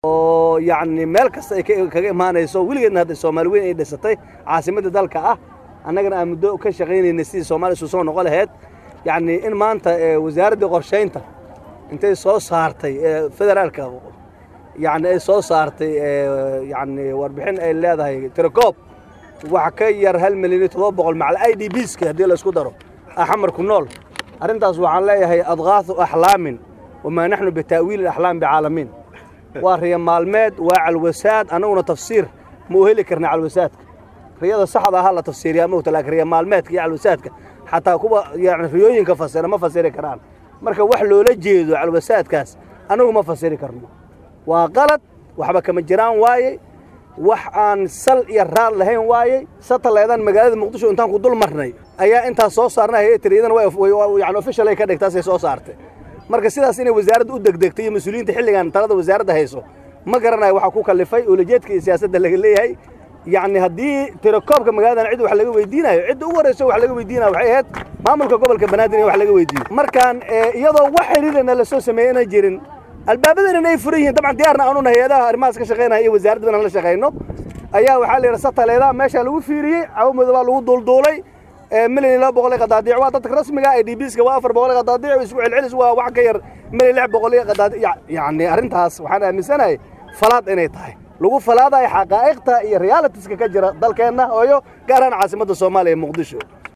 DHAGEYSO: Hadalka Duqa Muqdisho ee la Xiriira Tira-koobka Dadka Muqdisho